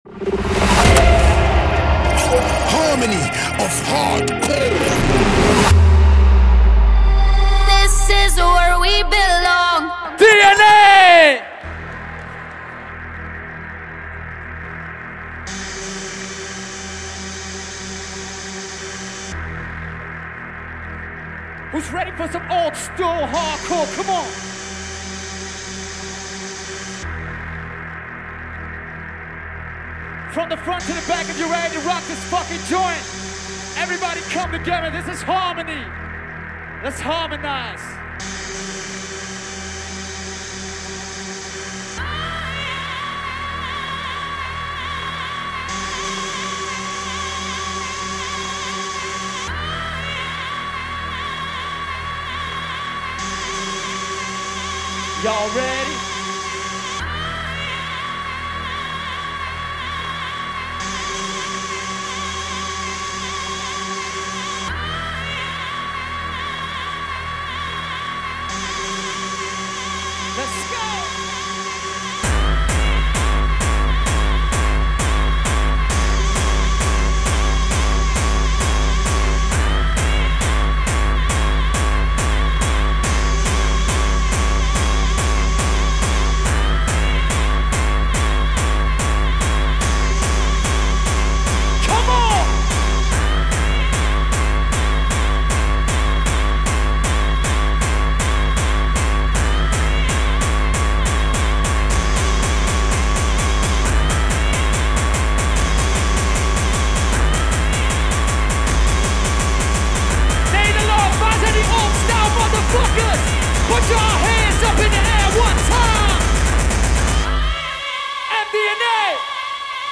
liveset